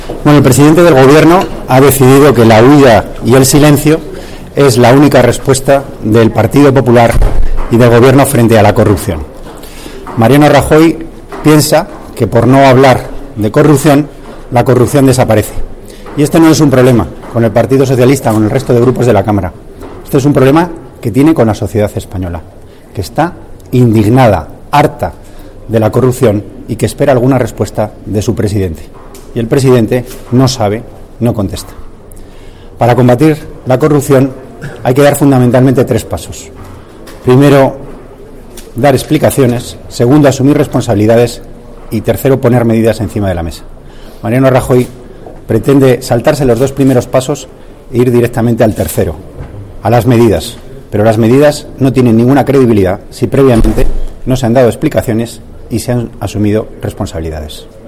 Declaraciones de Antonio Hernando tras la negativa del PP a convocar un pleno extraordinario la próxima semana para que Rajoy informe y dé explicaciones sobre los últimos asuntos relacionados con la corrupción y que afectan a su partido 30/10/2014